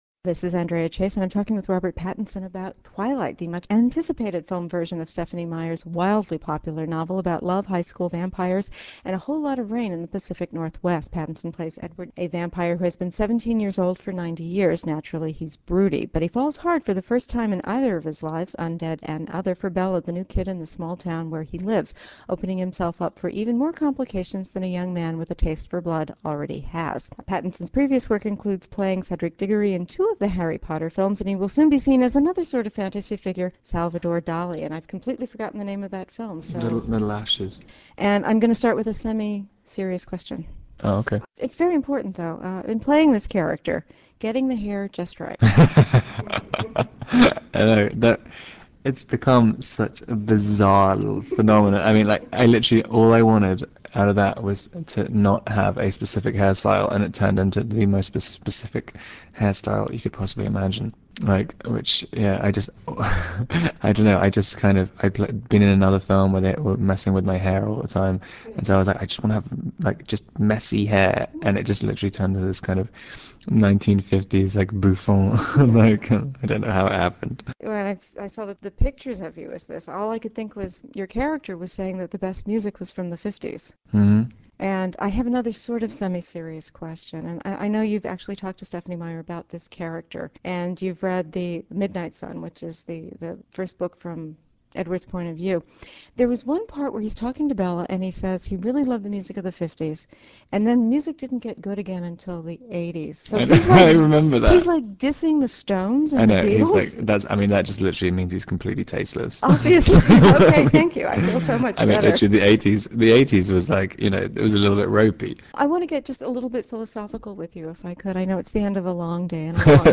When I talked with Robert Pattinson on November 10, 2008, about playing Edward, the hunky vampire in TWILIGHT, that film had just sold out around 100 of its first screenings almost two weeks before its opening. He modestly refused to take any personal credit for that, even though he had been mobbed more than once by eager fans during the press tour for that film.